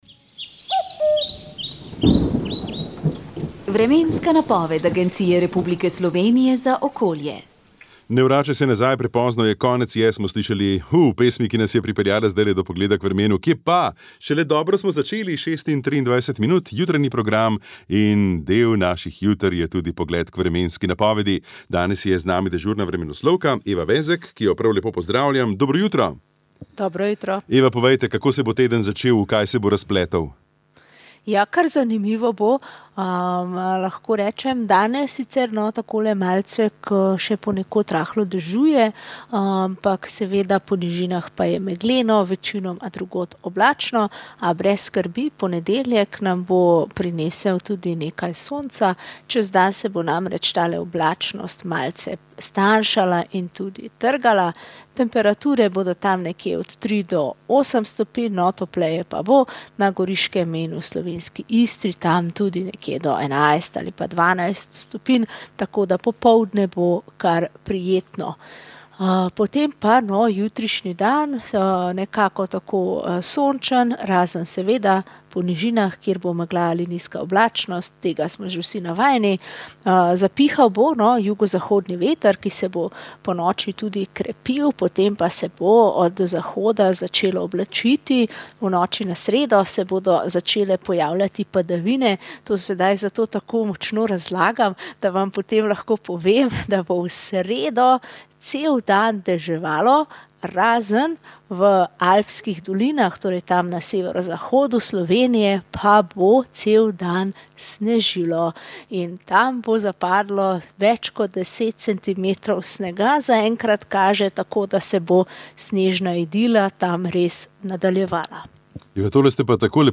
Priporočamo | Aktualno Komentar tedna VEČ ... | 2. 1. 2026 Medijska pristranskost: Od evtanazije do totalitarne dediščine Že pred referendumom o asistiranem samomoru sem postal pozoren na prispevke o tej tematiki.